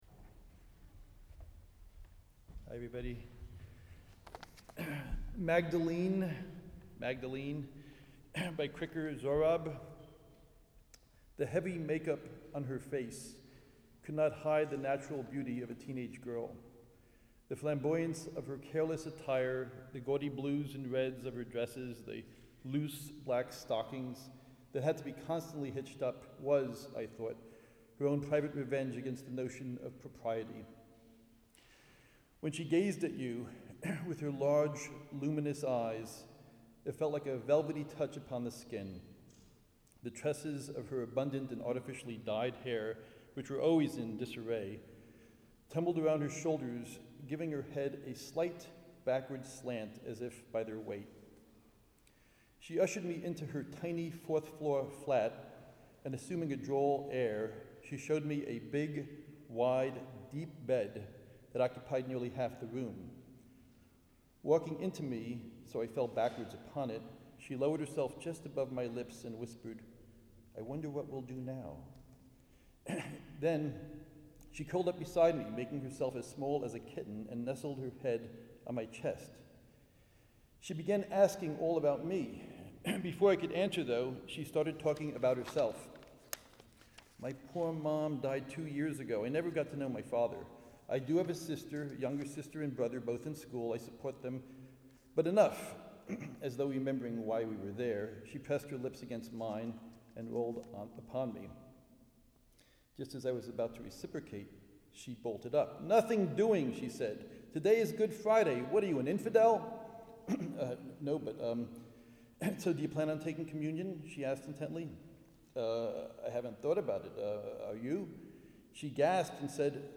Live from Holy Cross